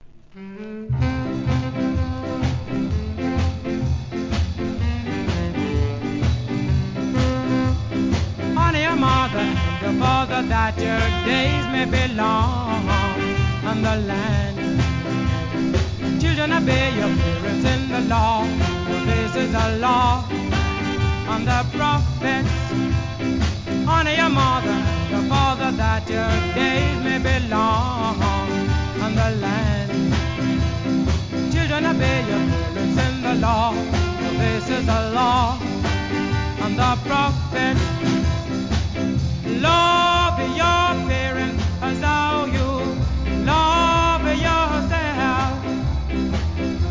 1. REGGAE